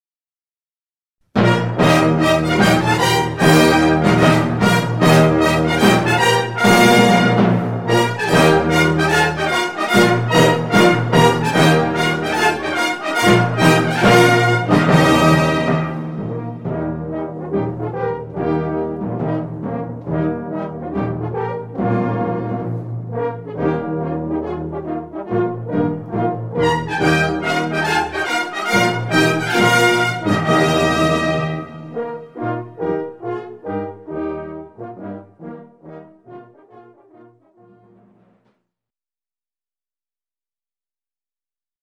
Répertoire pour Harmonie/fanfare - Batterie Fanfare